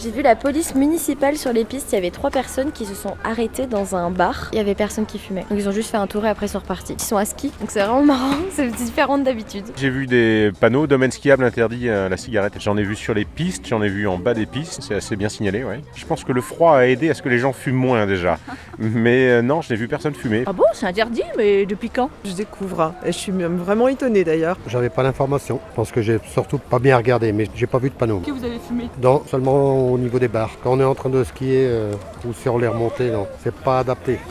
La rédaction de Radio Mont Blanc est allée demander aux skieurs s’ils avaient notifié l’information sur place.
ITL Microtrottoir 2 - interdiction cigarette gets 2026 2 _WM